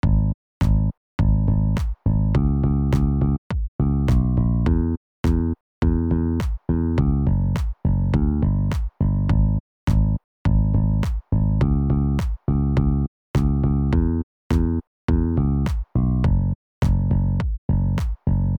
Помогите найти бас